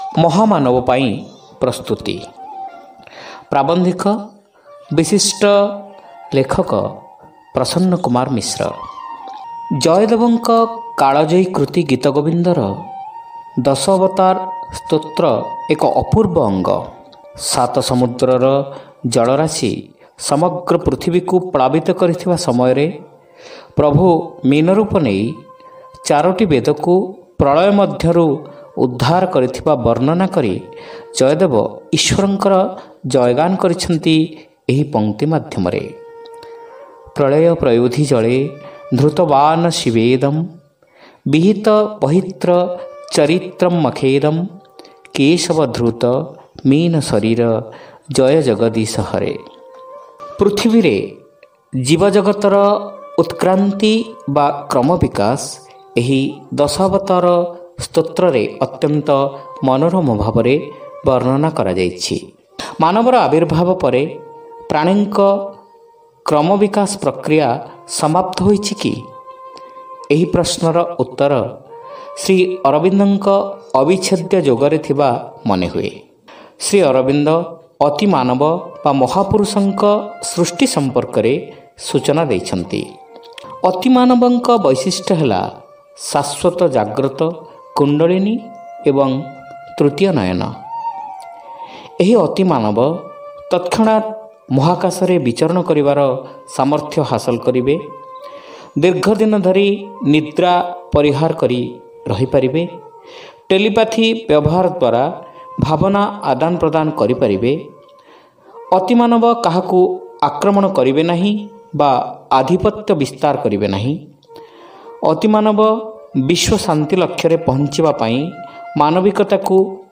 Audio Story : Mahamanaba Pain Prastuti